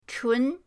chun2.mp3